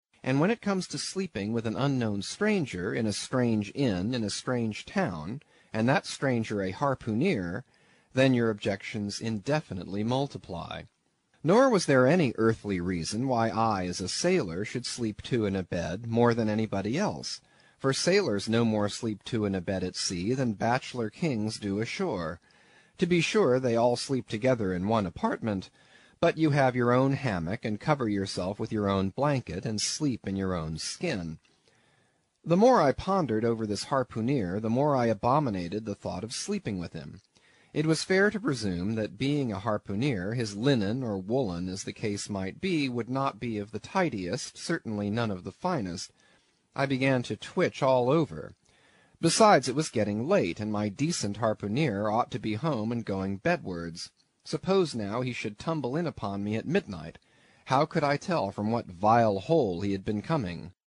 英语听书《白鲸记》第199期 听力文件下载—在线英语听力室